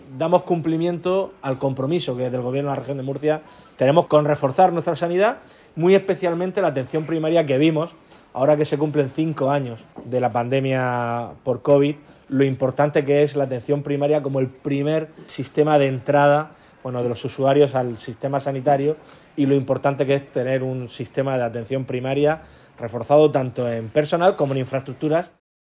Declaraciones del presidente de la Comunidad, Fernando López Miras, sobre el nuevo centro de salud de Corvera inaugurado hoy [mp3]